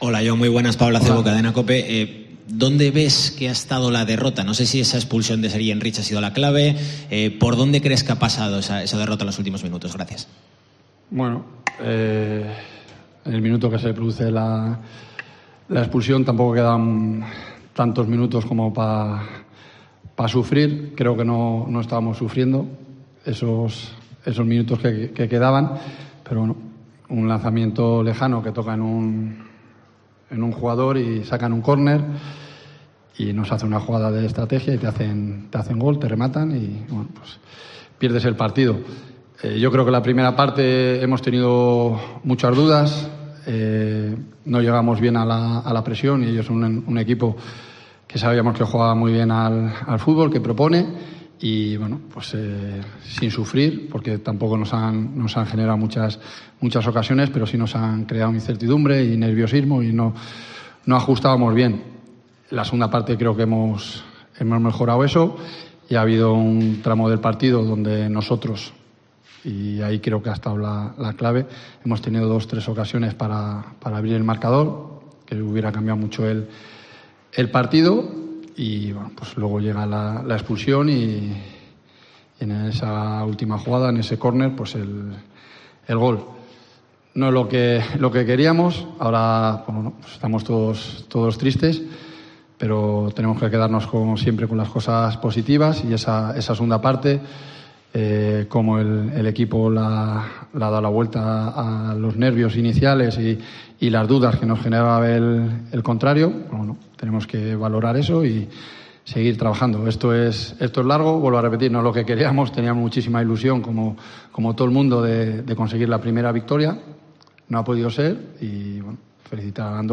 RUEDA DE PRENSA
Rueda de prensa Jon Pérez Bolo (post Andorra)
Jon Pérez Bolo compareció en sala de prensa después de la derrota en la primera jornada de LaLiga SmartBank frente al Andorra (0-1) en un choque donde los azules fueron inferiores en la primera mitad y mejoraron en la segunda, pero que terminaron perdiendo con un gol de Pau en el descuento cuando el Oviedo jugaba con diez jugadores por la expulsión de Sergi Enrich.